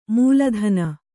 ♪ mūla dhana